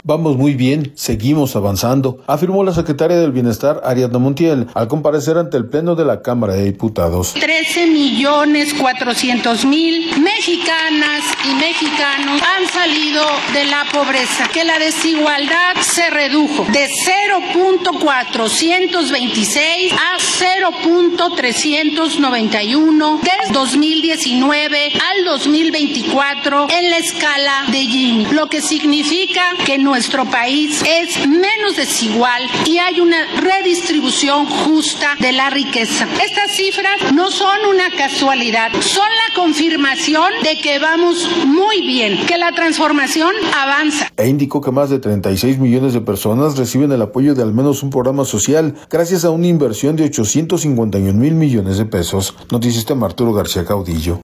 Vamos muy bien, seguimos avanzando, afirmó la secretaria del Bienestar, Ariadna Montiel, al comparecer ante el Pleno de la Cámara de Diputados.